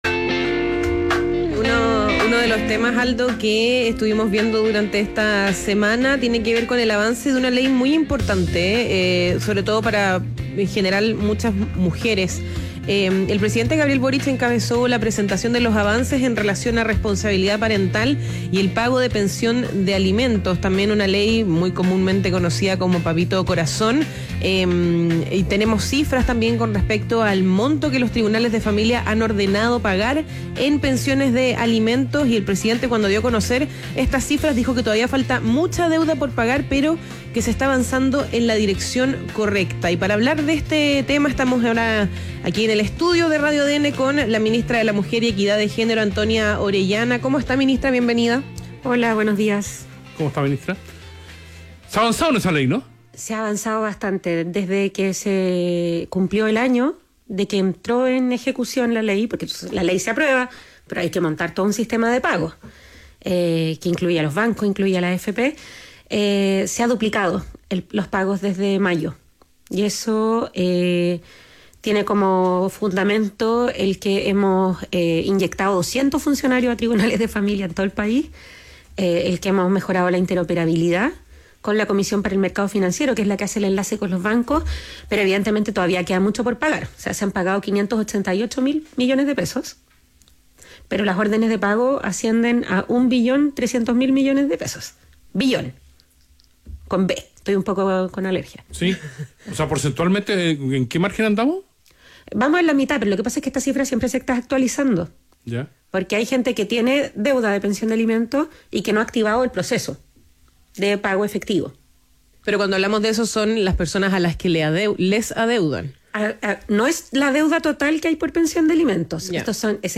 País ADN - Entrevista a Antonia Orellana, ministra de la Mujer y Equidad de Género